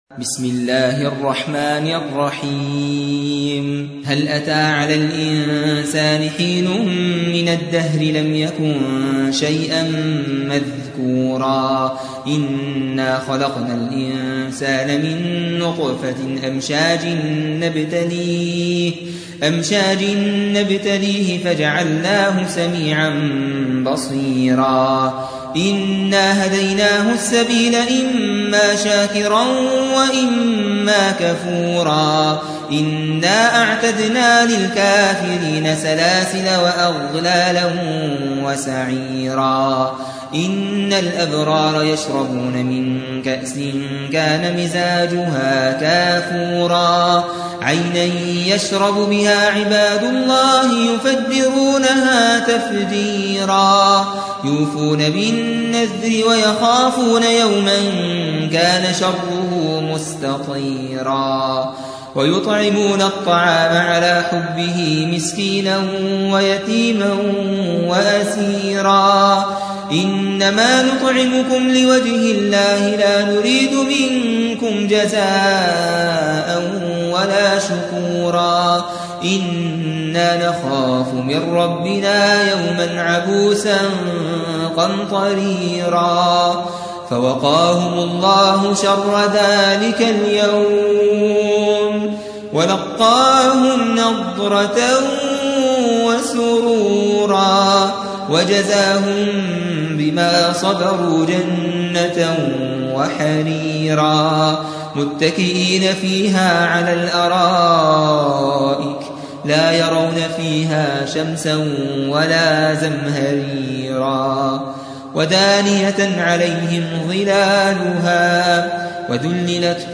76. سورة الإنسان / القارئ